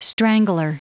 Prononciation du mot : strangler